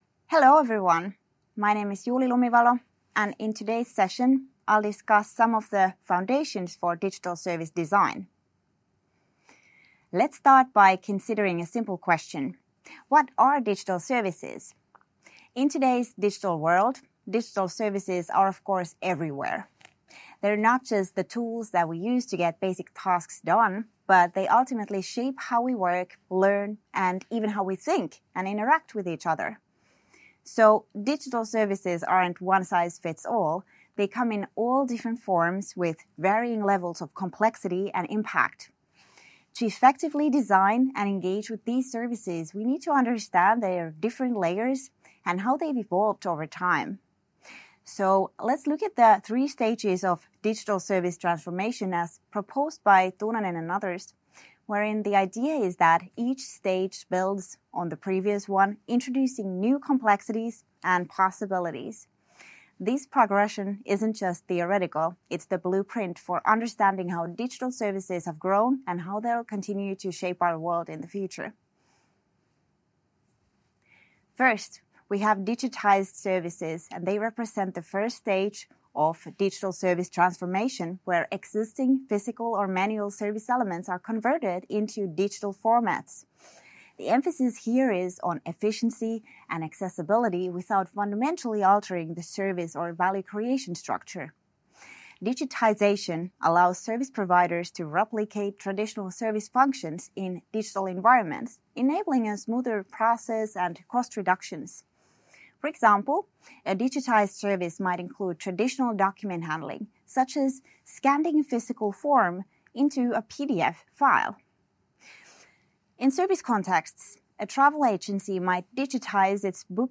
Week 1 Self-Study Video Lecture - Digital Service Design: Principles, Processes, and Value Co-Creation